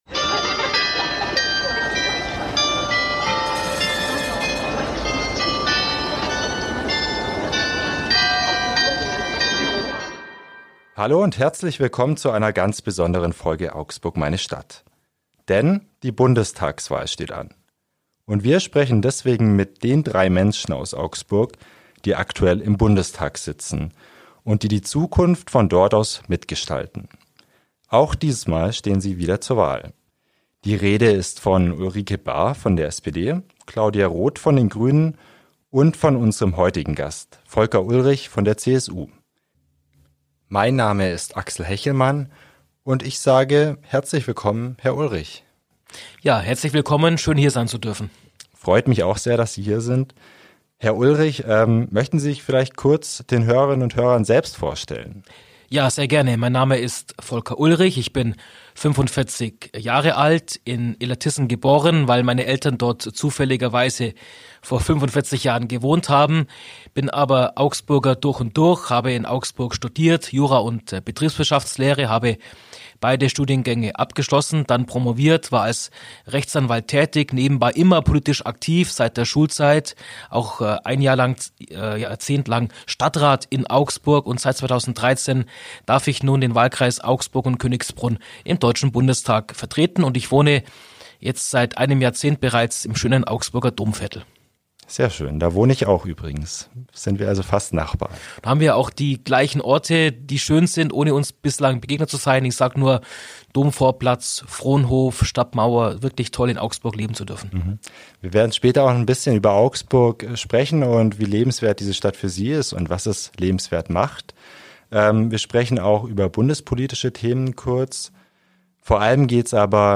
In dieser Folge unseres Podcasts "Augsburg, meine Stadt" spricht der CSU-Politiker Volker Ullrich über politische Ziele, Probleme in Augsburg und sein Privatleben. Ullrich will Arbeitsplätze im Raum Augsburg sichern, die Verkehrsanbindung verbessern und Familien entlasten.